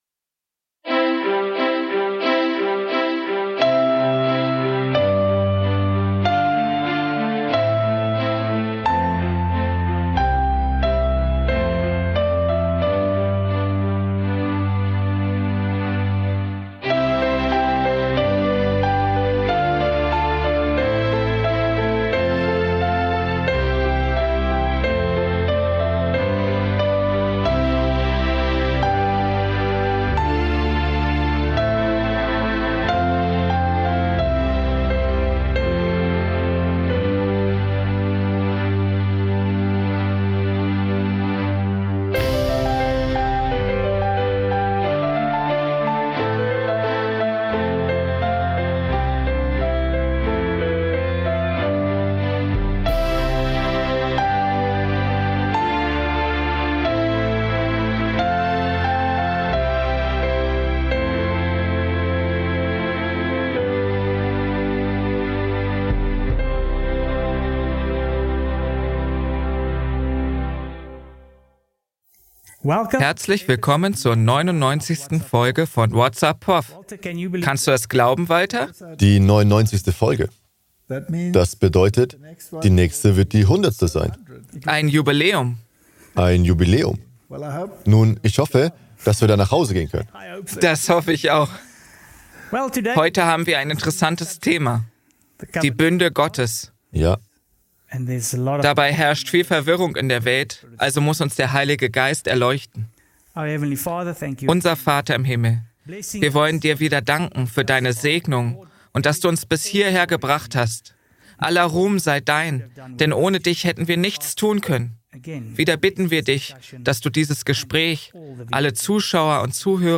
In der 99. Folge von What's Up Prof dreht sich alles um die Bünde Gottes und ihre Bedeutung für die Gegenwart. Ein spannendes Gespräch zeigt, wie diese geistlichen Vereinbarungen den Glauben, Gehorsam und das Verständnis der Menschheit prägen. Welche Rolle spielt der neue Bund im Erlösungsplan?